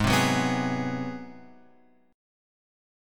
G#7#9 chord